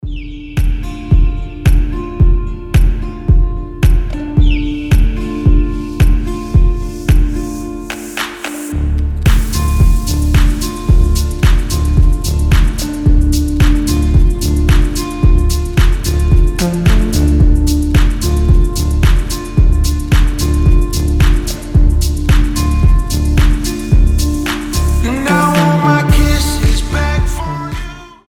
• Качество: 320, Stereo
гитара
deep house
мелодичные
Cover
медленные
расслабляющие